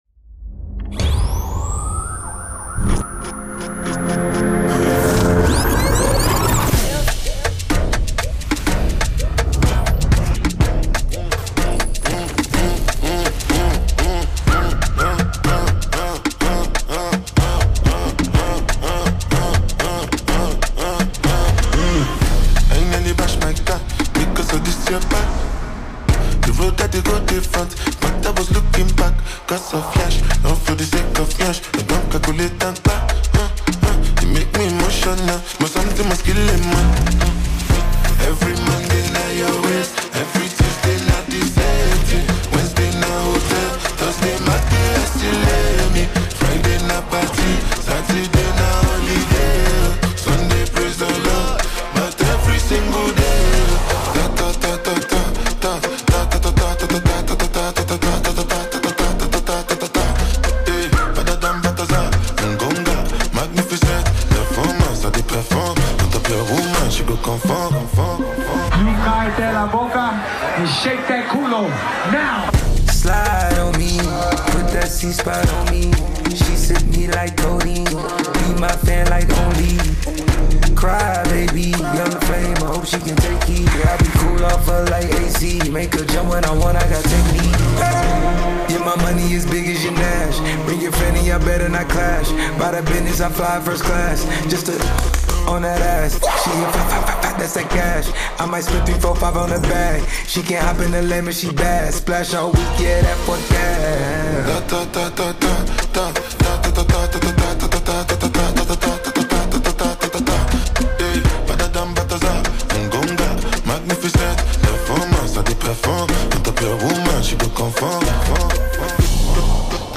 Upbeat and infectious
It layers rhythmic percussion and Afro-fusion melodies
delivers playful, confident lyrics